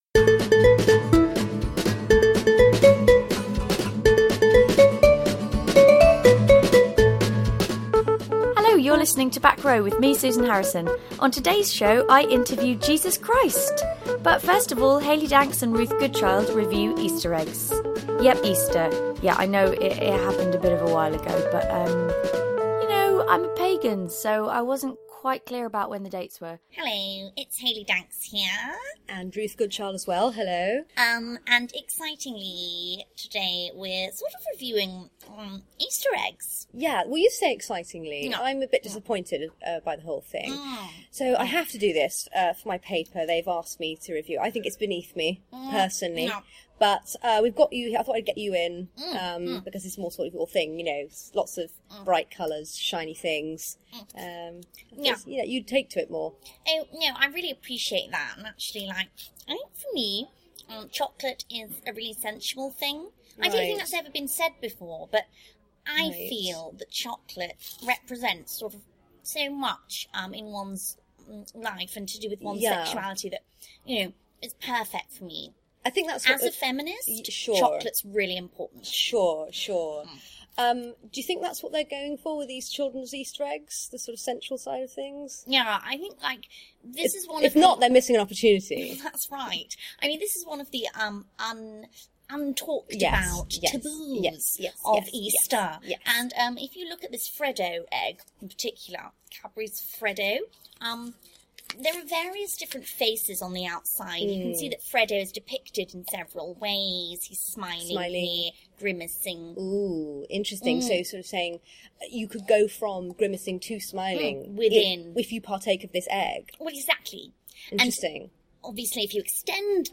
Spoof improvised podcast with some of the UK's finest up and coming character comedians and improvisers.